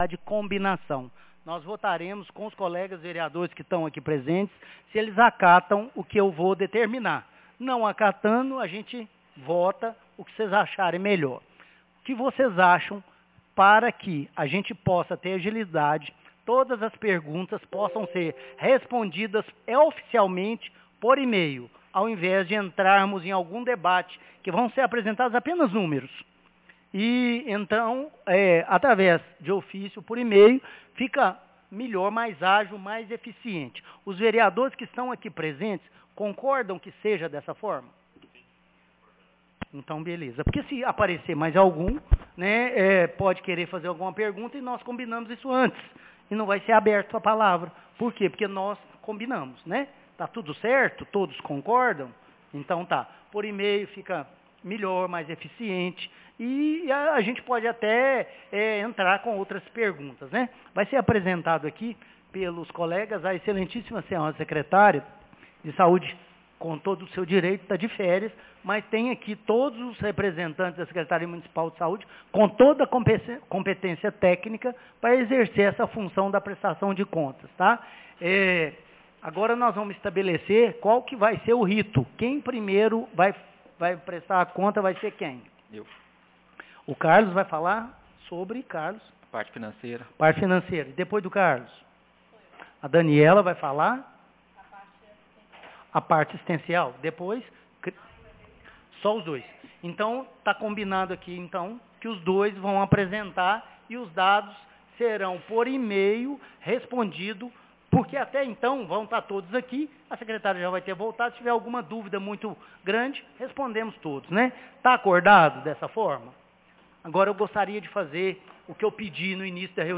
Audiencia Publica Semusa Prestaçao de contas 24 de fevereiro de 2026